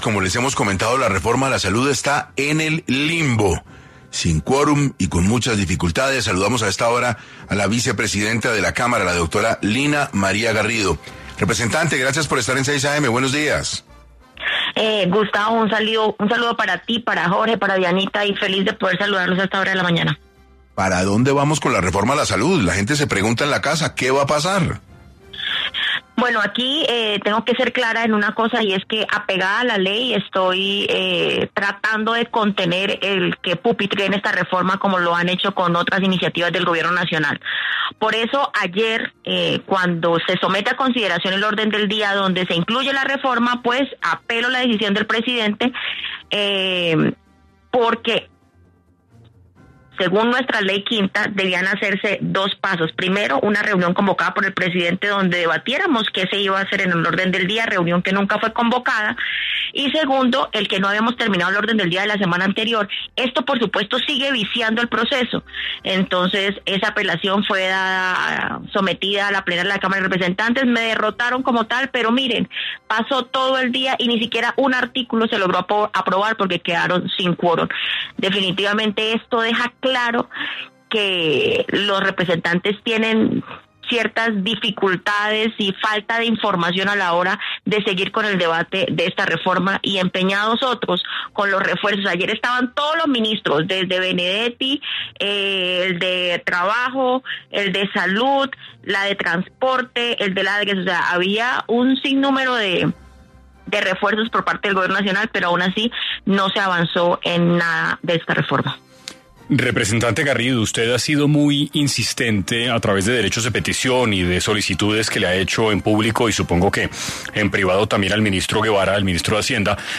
Lina María Garrido, segunda vicepresidenta de la Cámara de Representantes, estuvo en 6AM para abordar la discusión alrededor de la Reforma a la Salud.
En esta coyuntura, la Representante estuvo tras los micrófonos de 6AM para ampliar la discusión.